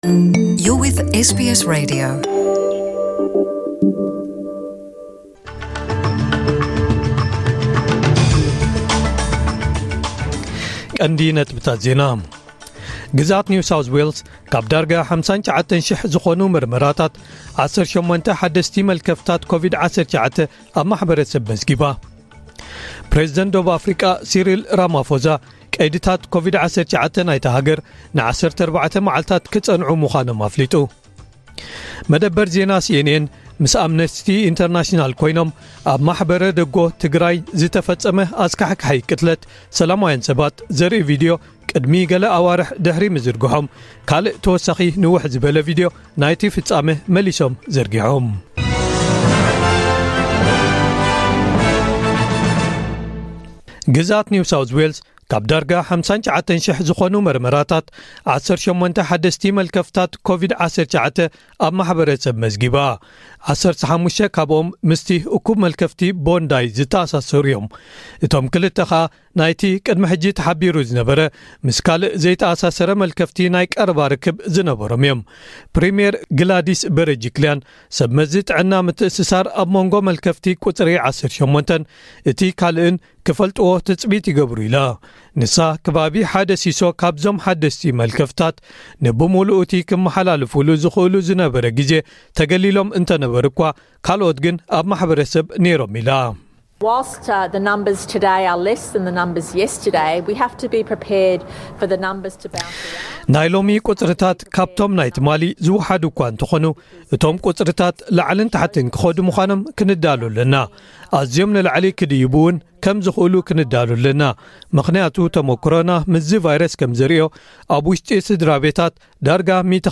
ዕለታዊ ዜና ኤስቢኤስ ትግርኛ (28/06/2021)